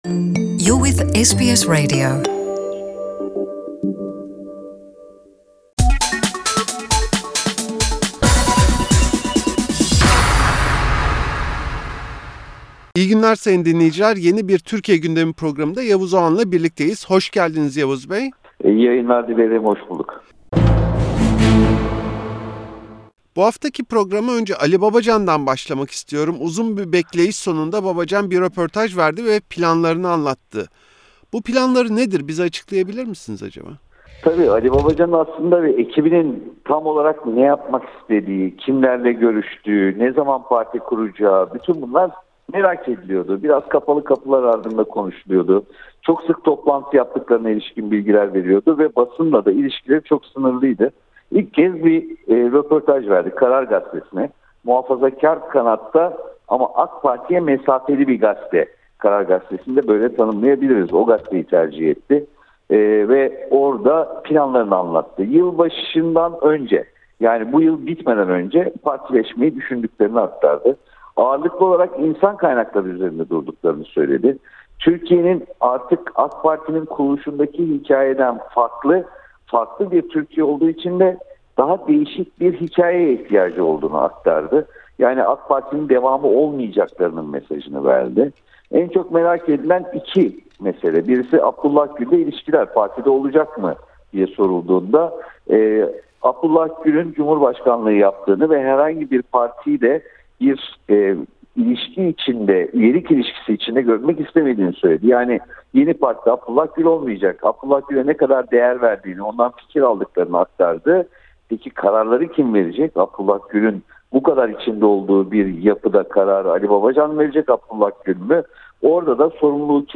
Gazeteci Yavuz Oğhan, SBS Türkçe’ye verdiği röportajda, eski Başbakan Yardımcısı Ali Babacan’ın röportajı, İstanbul Büyükşehir Belediye Başkanı Ekrem İmamoğlu’nun kırık sandalyesi ve gündem yaratan rap şarkısı SUSAMAM hakkında konuştu.